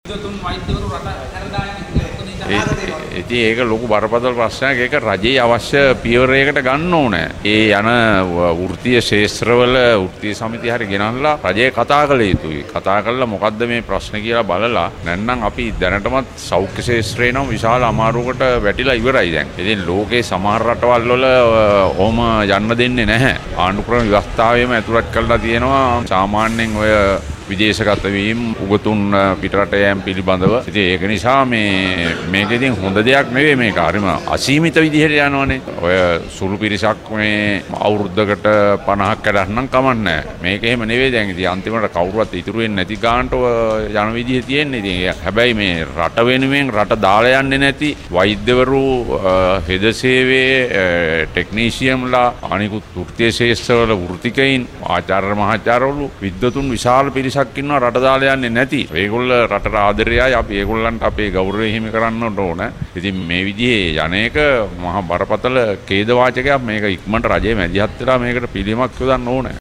මාධ්‍ය වෙත අදහස් පල කරමින් හිටපු ජනාධිපති මෛත්‍රිපාල සිරිසේන මහතා මේ බව අවධාරණය කළා .